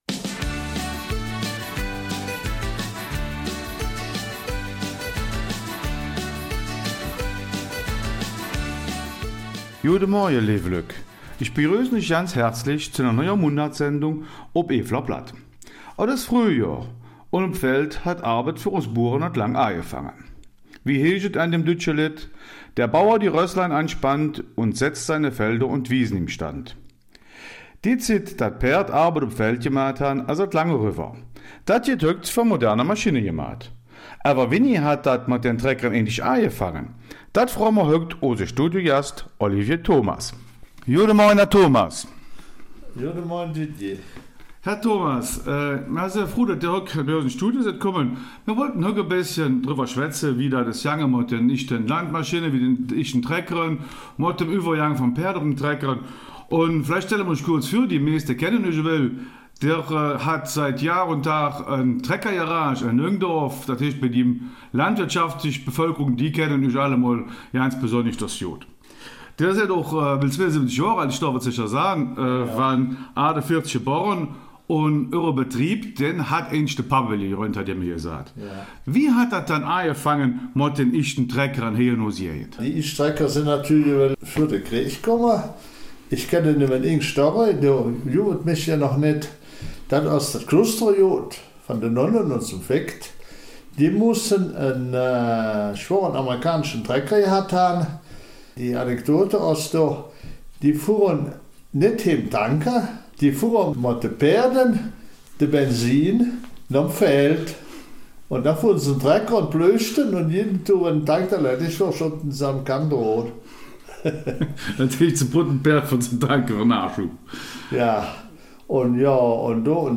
Die Eifeler Mundartsendung steht unter dem Motto ''Vom Ackergaul zum modernen Traktor''.